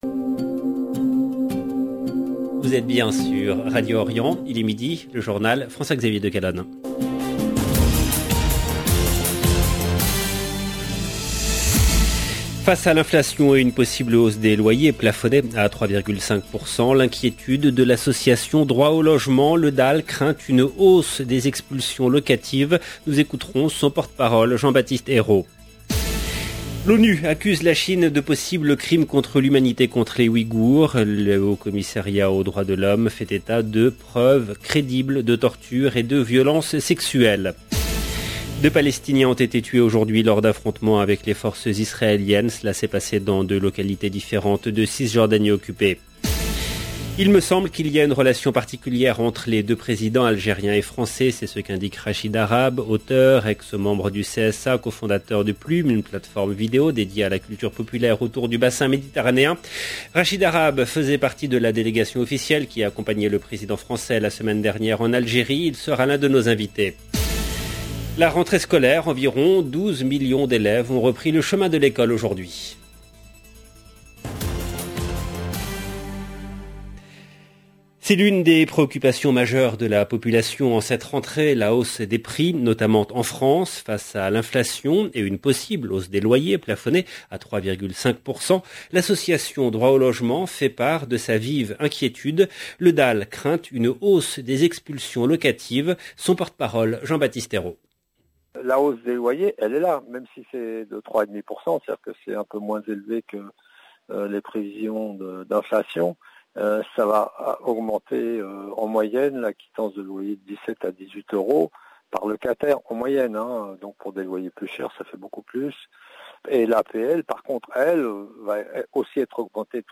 LE JOURNAL EN LANGUE FRANCAISE DE MIDI DU 1/09/22